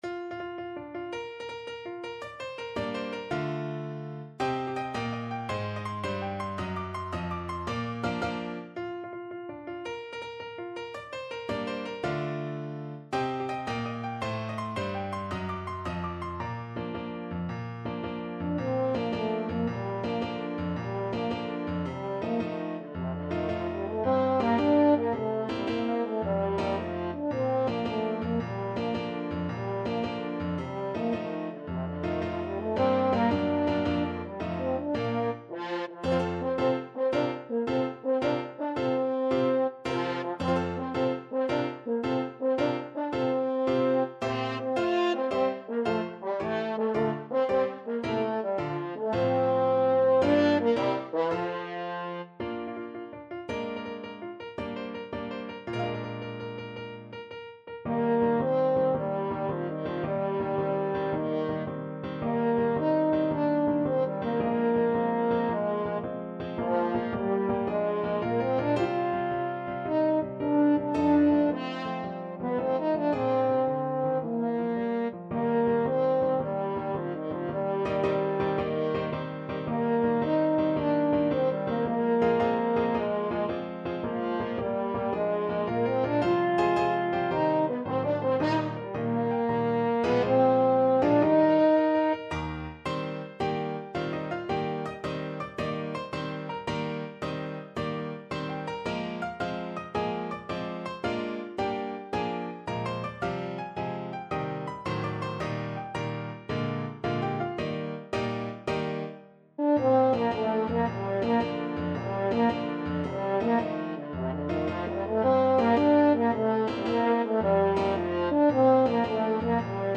French Horn
Bb major (Sounding Pitch) F major (French Horn in F) (View more Bb major Music for French Horn )
March .=c.110
6/8 (View more 6/8 Music)
Classical (View more Classical French Horn Music)